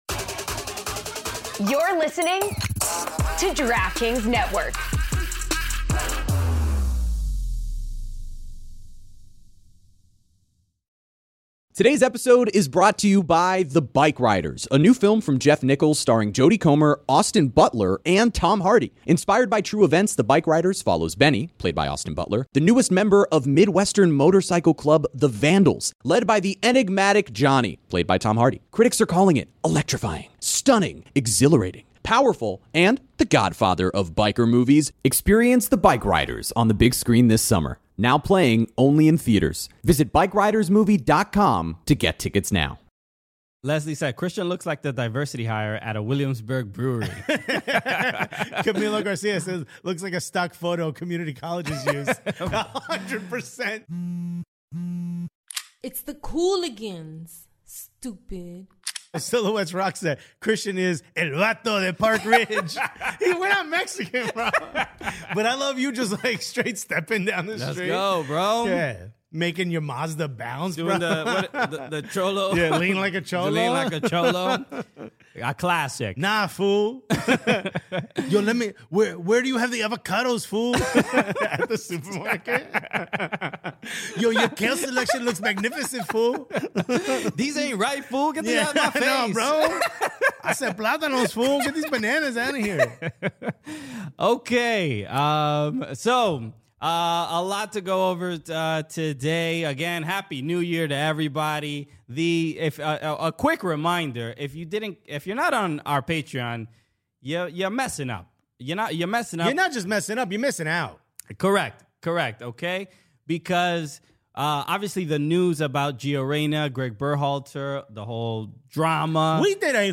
The Cooligans are two new york latino stand up comedians who love soccer/football/futbol/calcio and do live streams, podcasts, and youtube content around the sport.